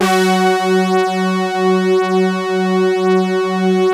BRASS2 MAT.1.wav